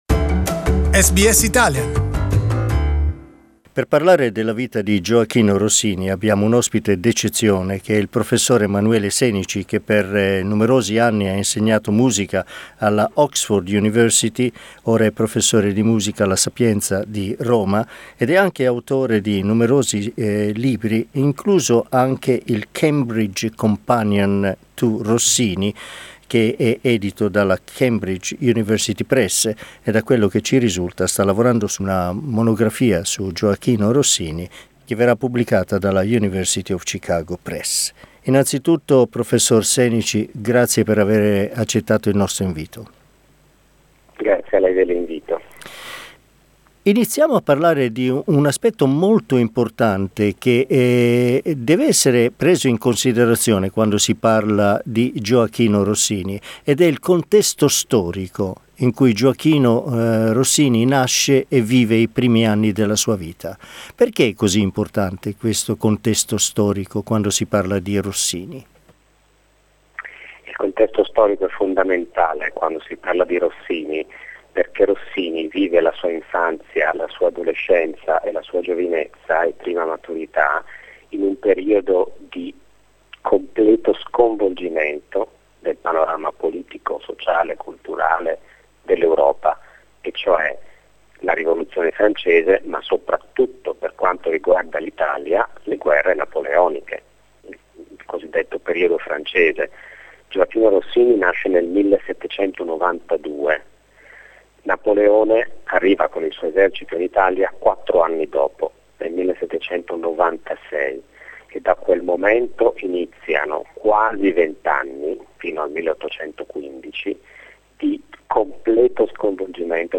In questa serie ascolteremo alcuni dei maggiori esperti della vita e della musica di Rossini, oltre ad alcuni brani rappresentativi del ricco repertorio musicale rossiniano.